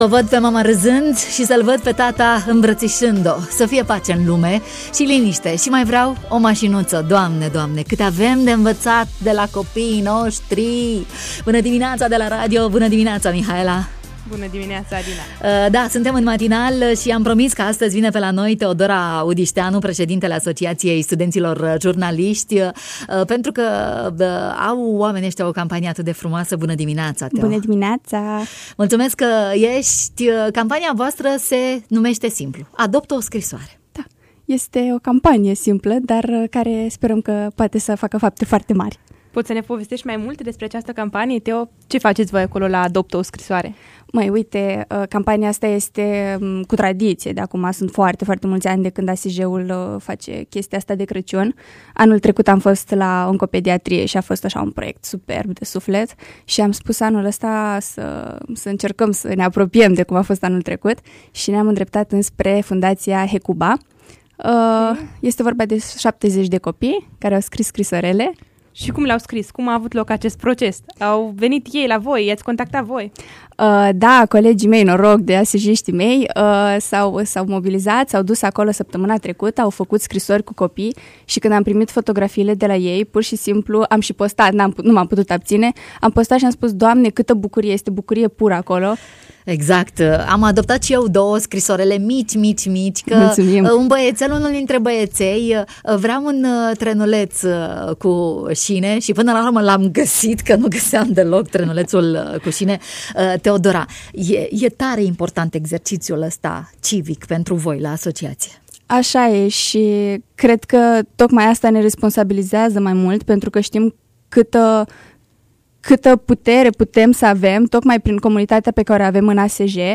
în matinal